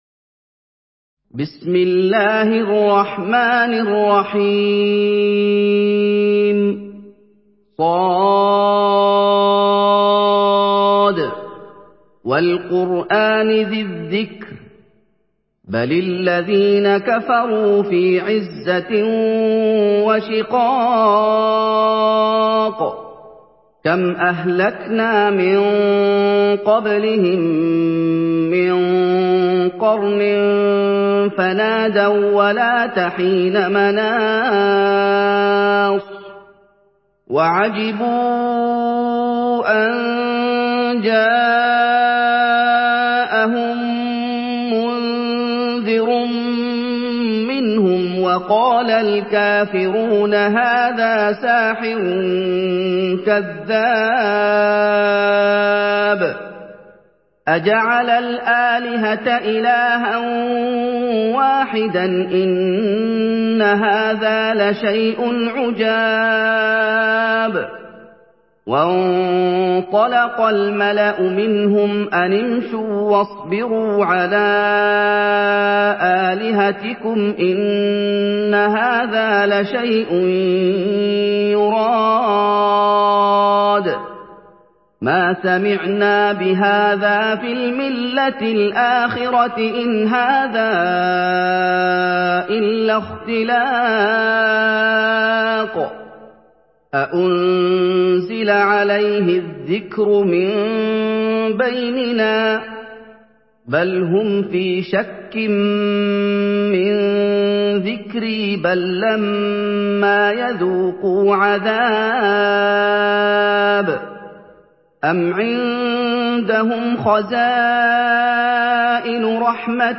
Surah Sad MP3 by Muhammad Ayoub in Hafs An Asim narration.
Murattal Hafs An Asim